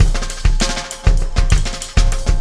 Extra Terrestrial Beat 09.wav